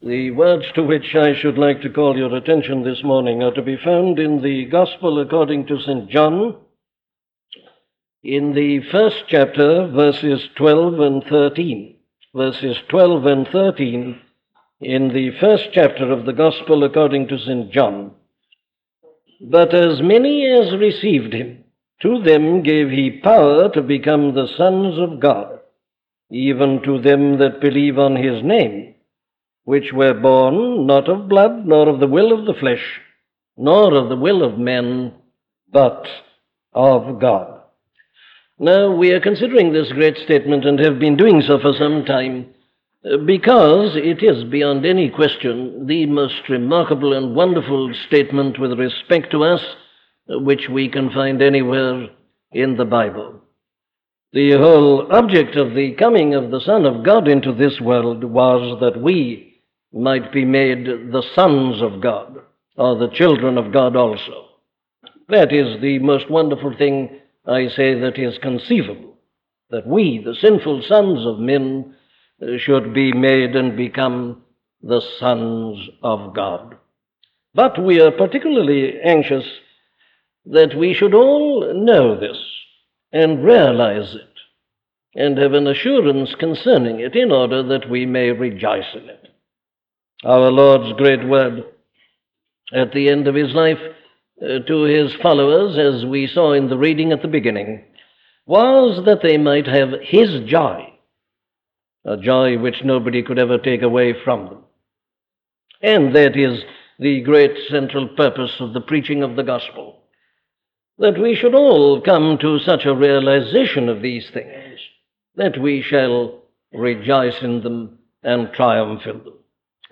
Free Sermon | Sermons on Christian Assurance | Page 1 of 3
A collection of sermons on Sermons on Christian Assurance by Dr. Martyn Lloyd-Jones